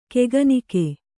♪ keganike